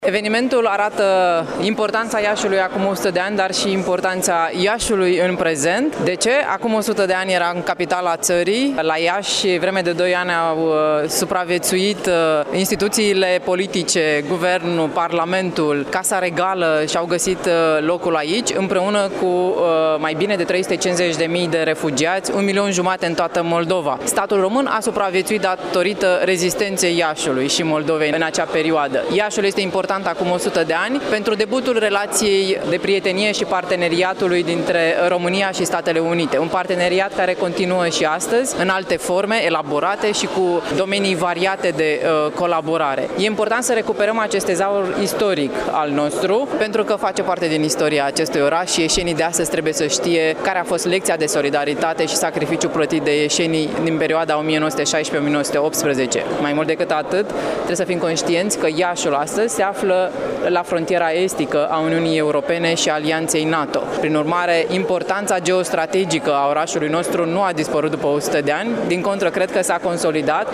Astăzi, la Iaşi, se desfăşoară Conferinţa cu tema ’’Provocări de securitate în sud-estul Europei’’.
Vicepreşedintele Senatului, senatorul PNL de Iaşi, Iulia Scântei, co-organizator al evenimentului: